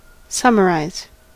Ääntäminen
US : IPA : [ˈsʌ.mə.ˌrɑɪz]